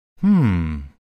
Hmm Sound Effect Free Download